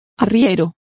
Complete with pronunciation of the translation of muleteer.